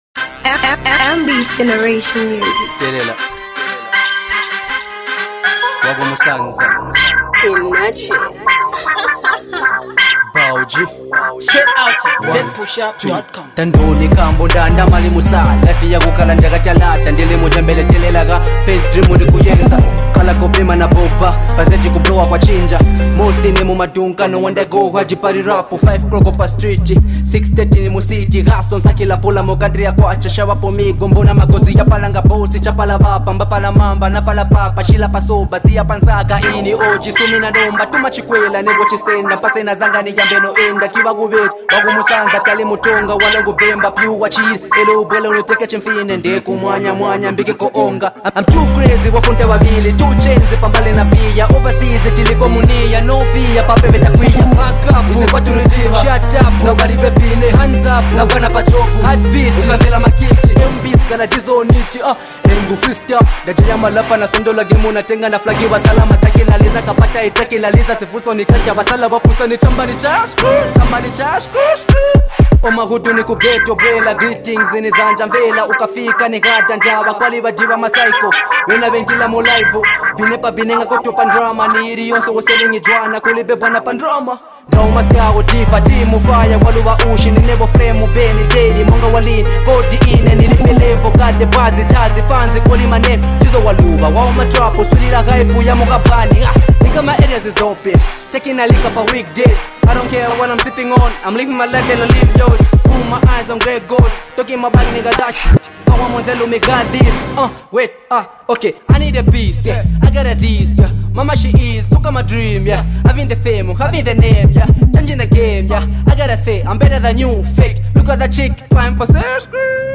Tonga Trap Genre track